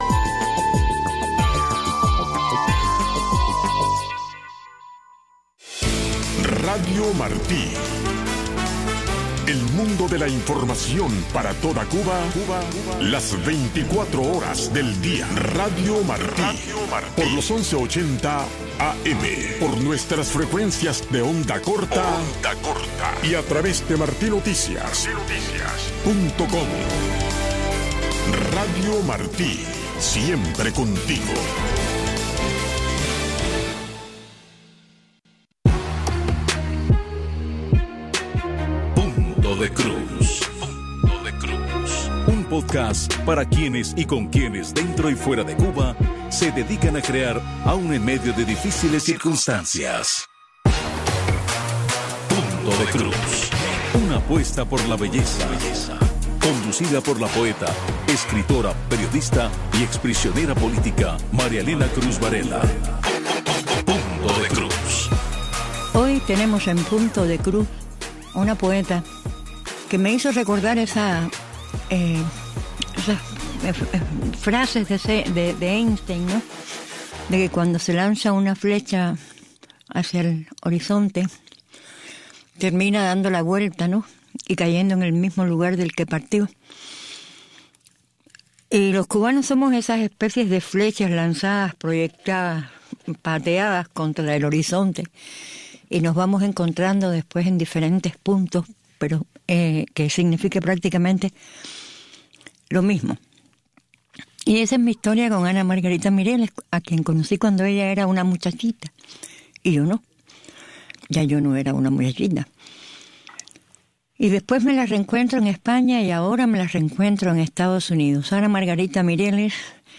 Las voces que testimonian la vida del cubano de a pie.